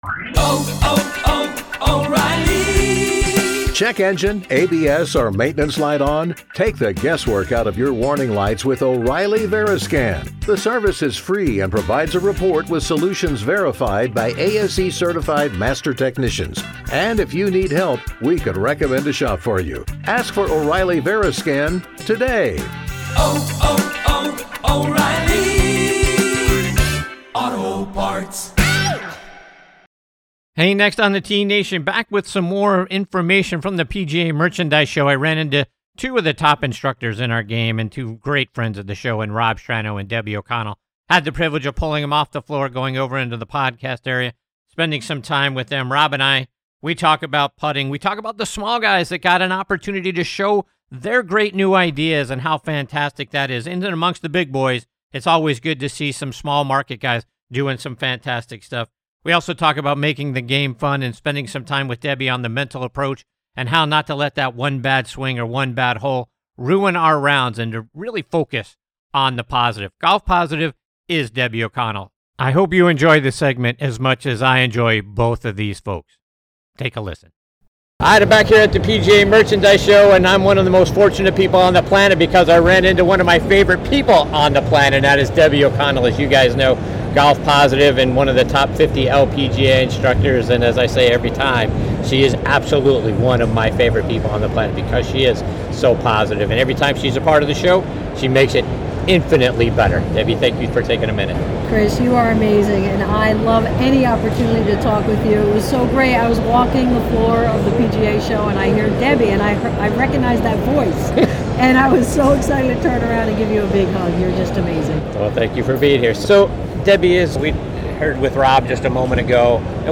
Golf Podcast: next on the tee / Live From the Floor of the PGA Merchandise Show